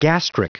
Prononciation du mot gastric en anglais (fichier audio)
Prononciation du mot : gastric